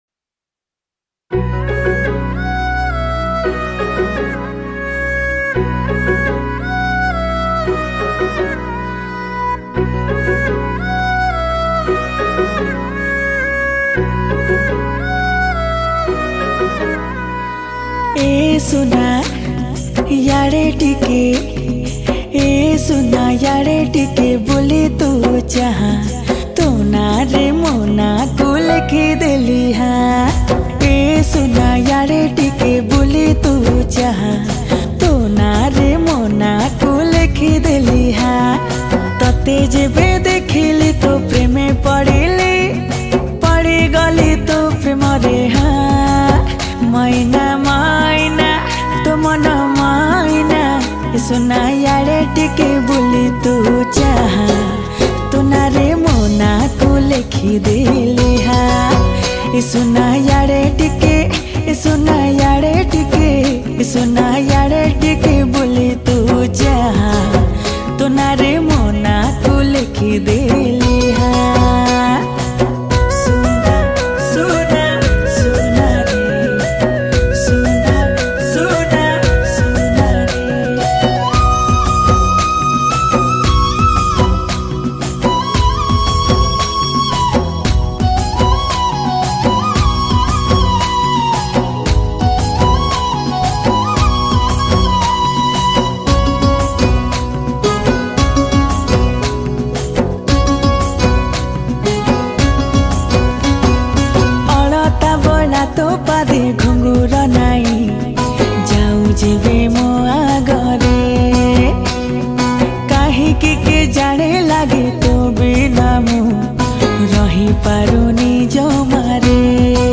Keaboard